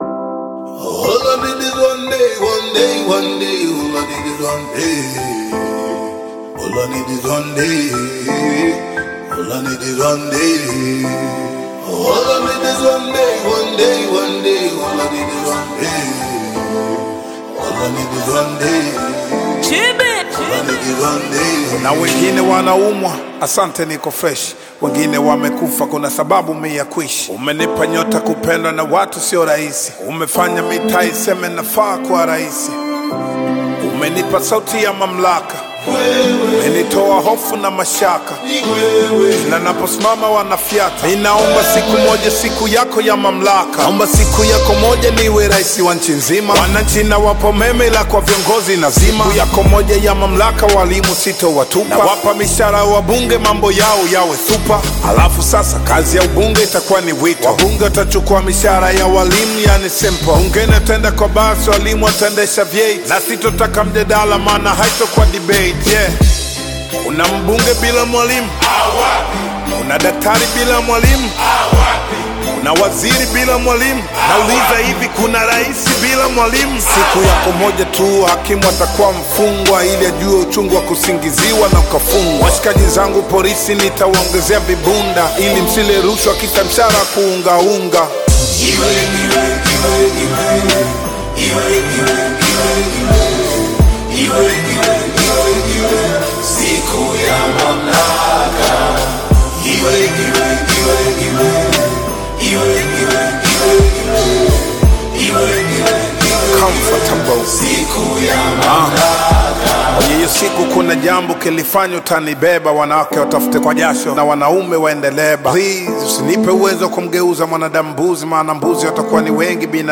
Tanzanian Bongo Flava
hip-hop songs
African Music